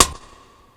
just blaze verbsnaprim.WAV